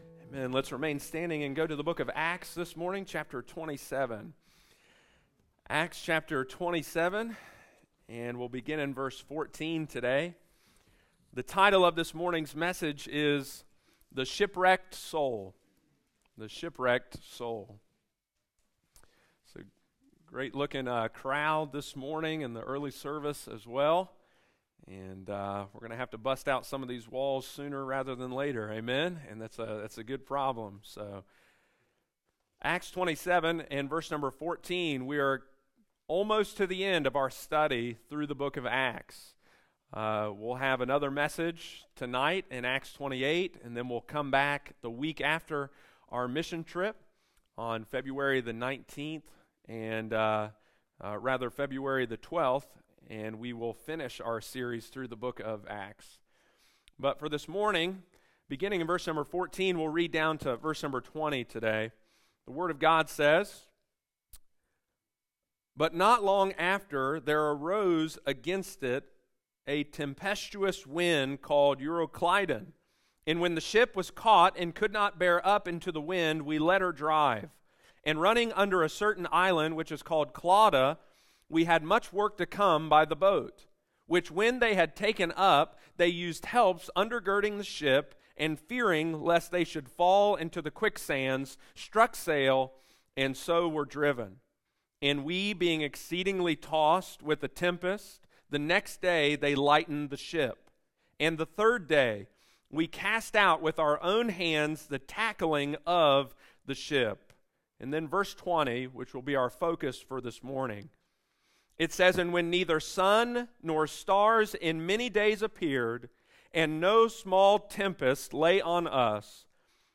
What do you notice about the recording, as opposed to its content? Jesus is able to rescue us from the sea of our sin and bring us safely to shore! Sunday morning, January 29, 2023.